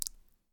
water-click
bath bathroom bathtub bubble burp click drain drip sound effect free sound royalty free Nature